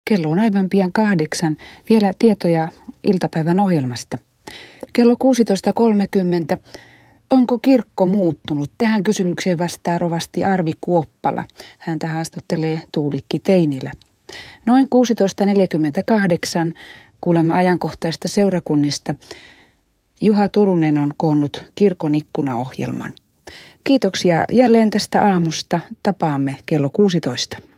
Radio 951:n aamulähetyksen päätössanat vuonna 1985.
Artikkelissa olevien ääninäytteiden laadun parantamisessa on käytetty tekoälysovellusta.